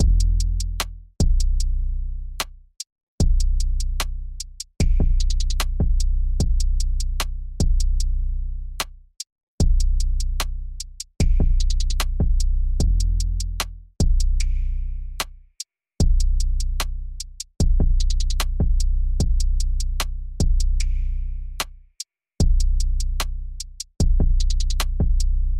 没有了 鼓
描述：没有808，但之前上传的75bpm的鼓声
Tag: 75 bpm Trap Loops Drum Loops 4.32 MB wav Key : G